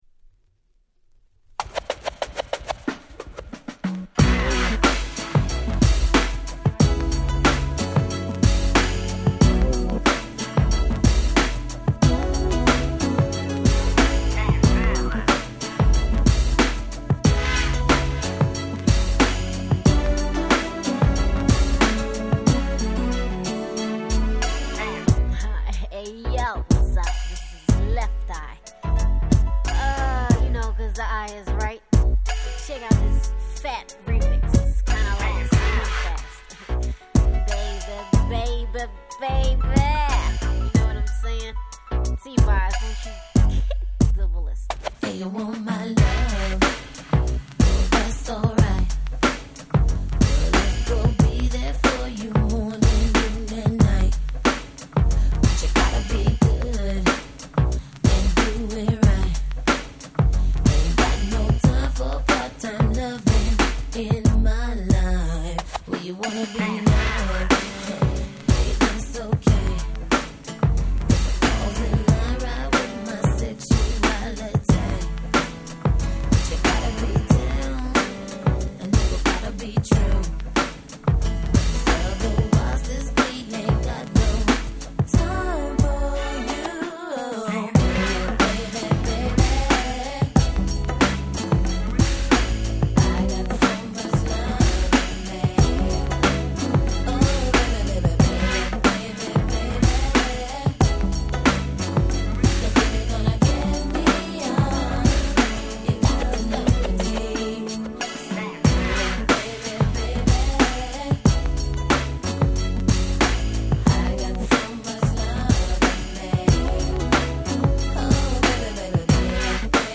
92' Big Hit R&B !!
問答無用の90's R&B Classics !!